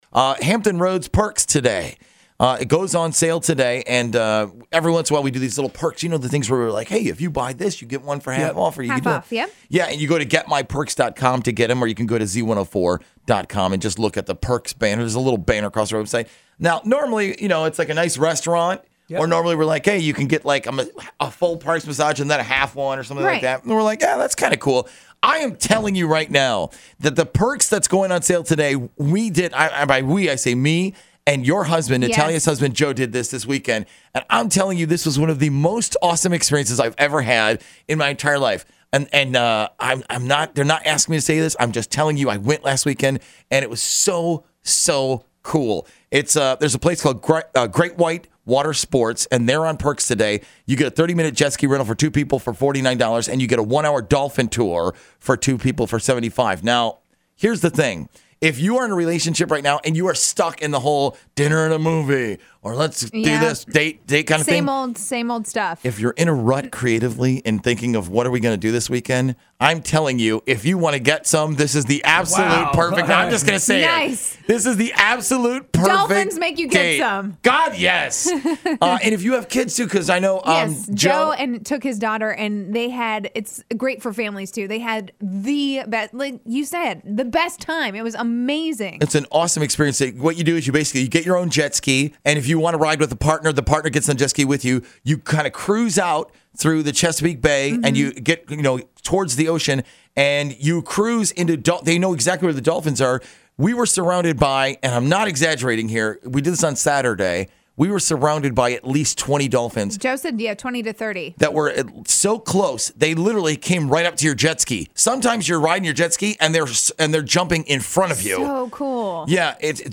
Great-White-Water-Sports-radio-endorsement-1.mp3